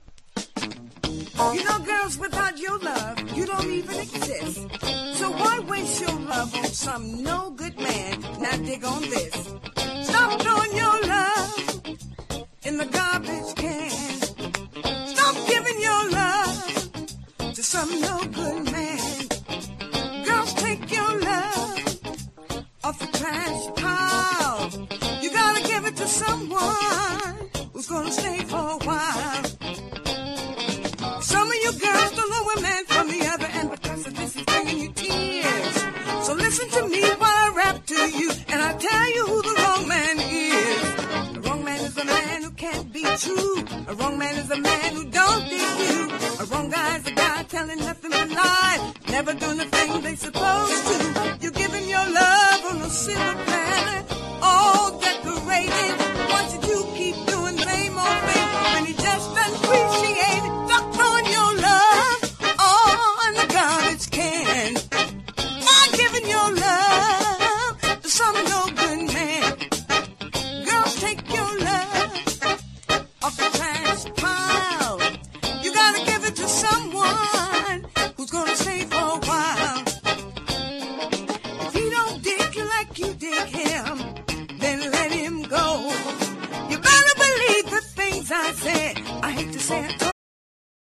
# FUNK / DEEP FUNK